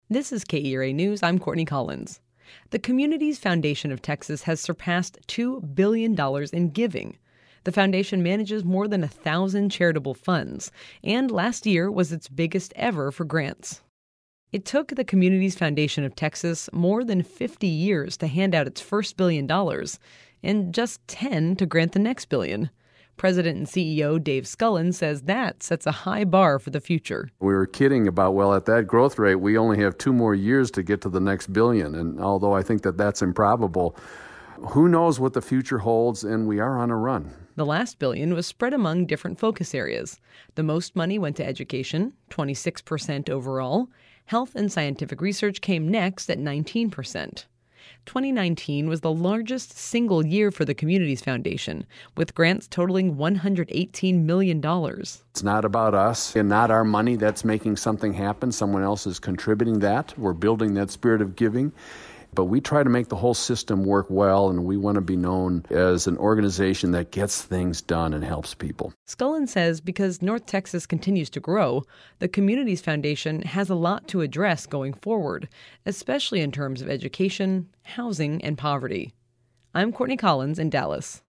The KERA radio story